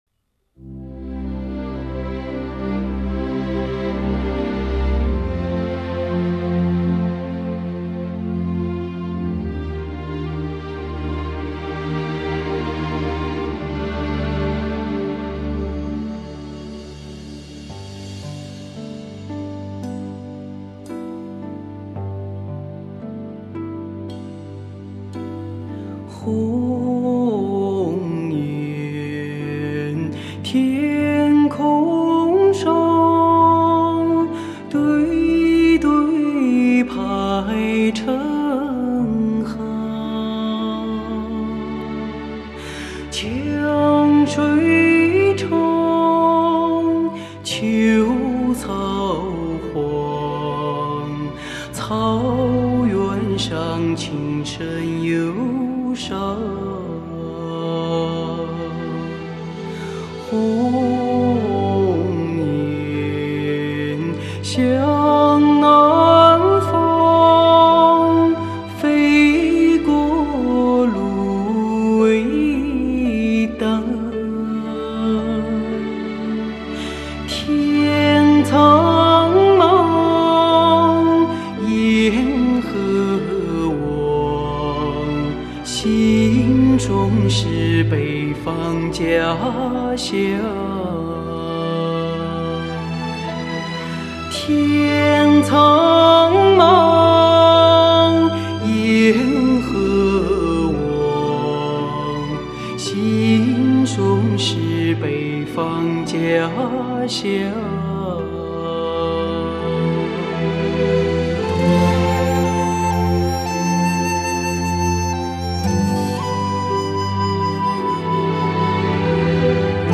最美的女中音，悠远飘荡的旋律，苍茫里沉静的吟唱。
浓烈的高原风情、嘹亮的藏地